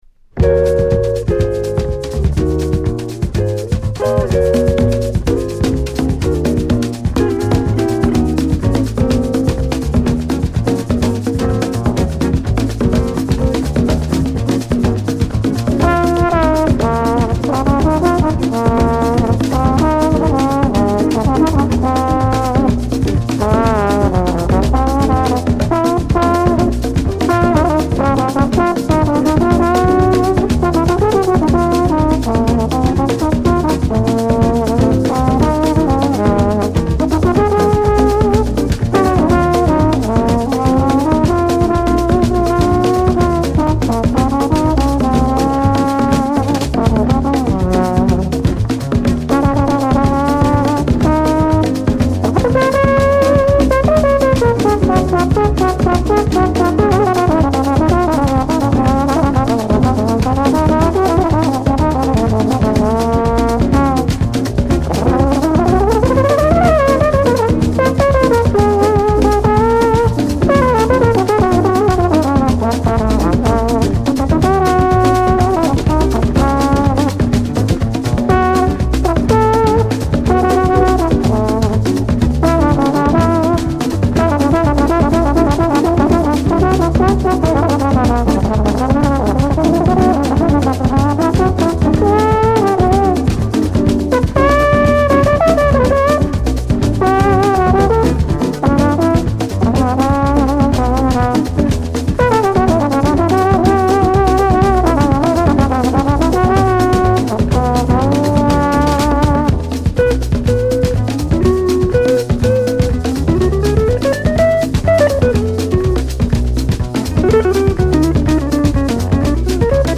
Jazz Groove denmark